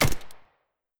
New gunshot SFX
gunshotSM.wav